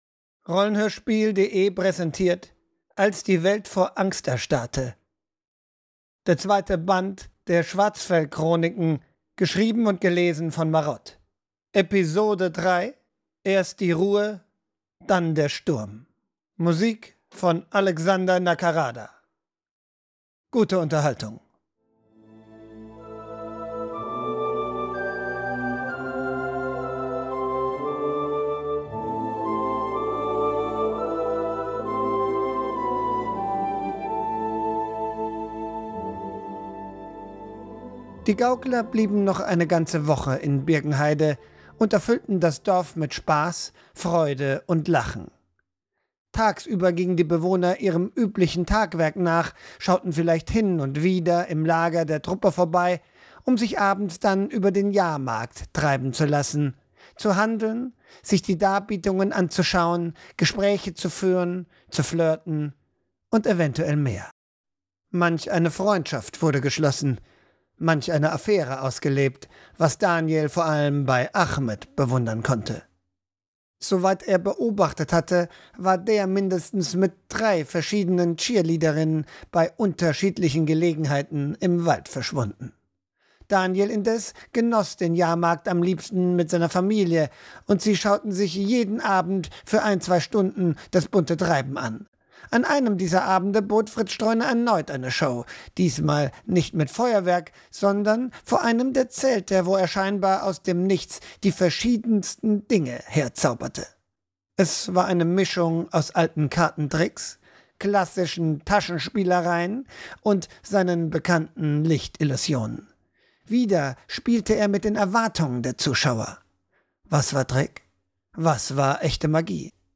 (3) Als die Welt vor Angst erstarrte (Apokalypse) (Fantasy) (Hörbuch) (Schwarzfell)